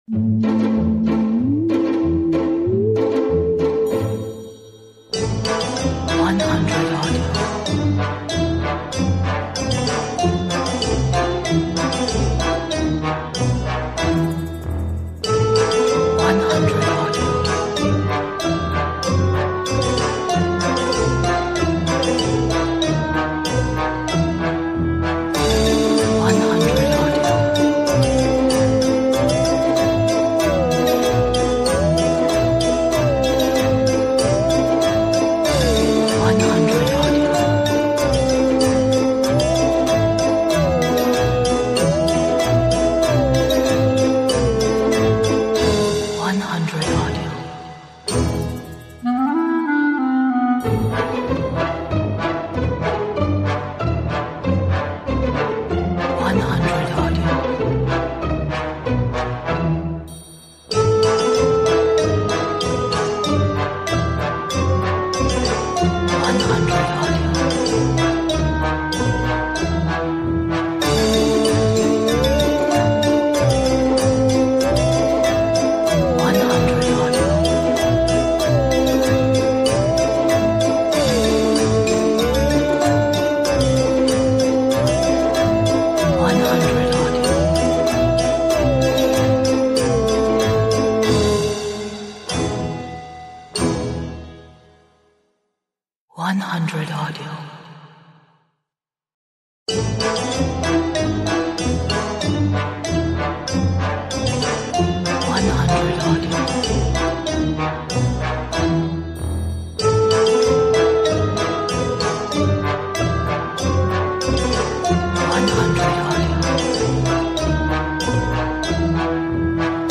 Background, cinematic, creepy, dark, evil